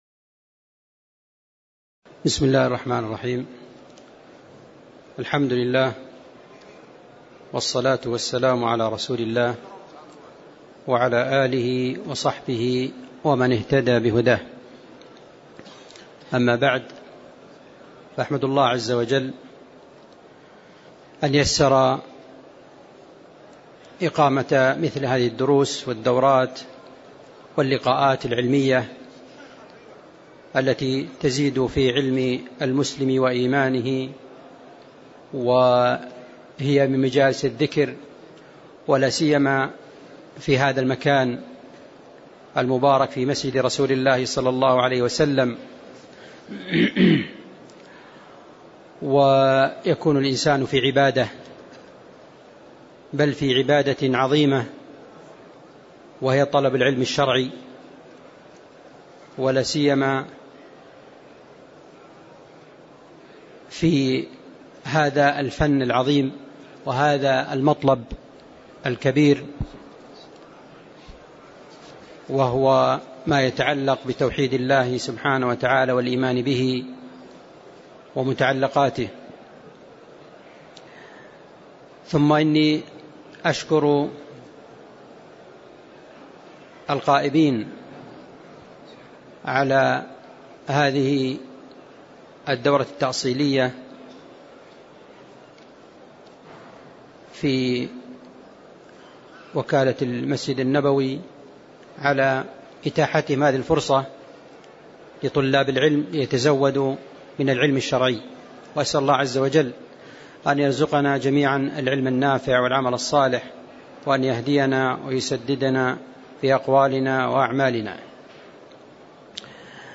تاريخ النشر ١١ شوال ١٤٣٨ هـ المكان: المسجد النبوي الشيخ